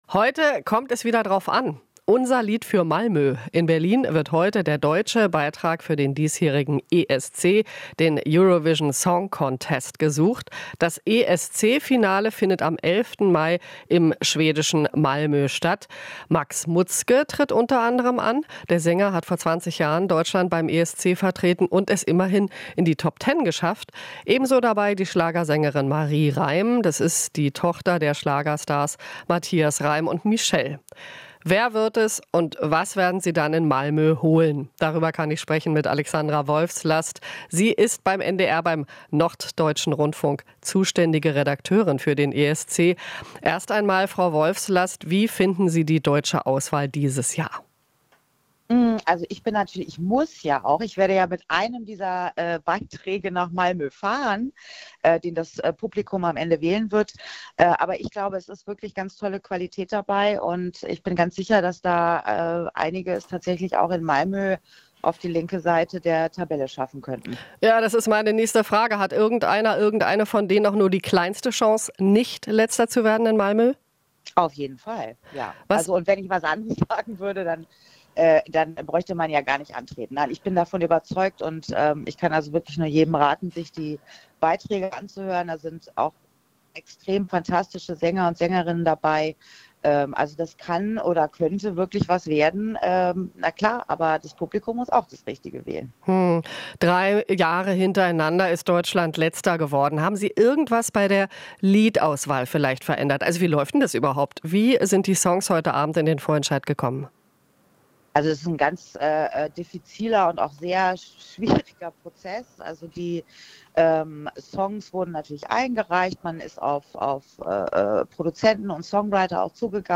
Interview - Deutscher ESC-Vorentscheid: "Eine Achterbahn der Gefühle"